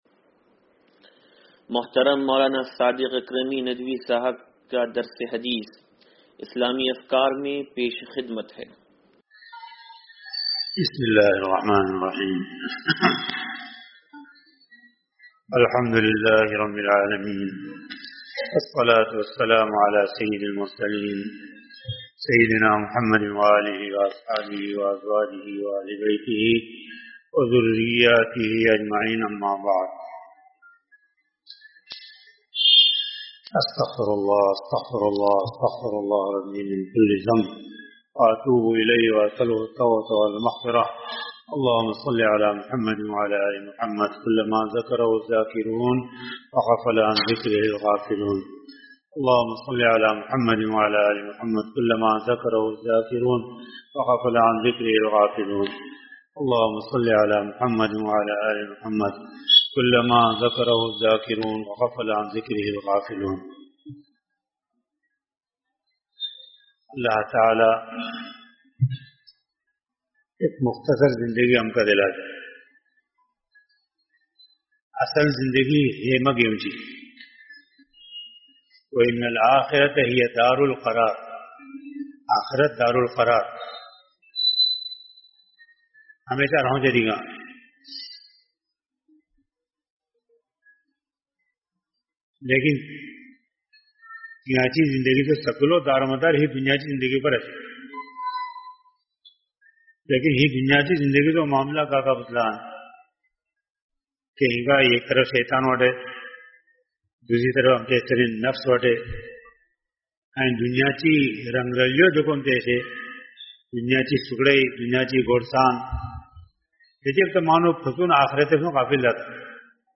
درس حدیث نمبر 0571